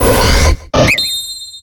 Cri de Gallame dans Pokémon X et Y.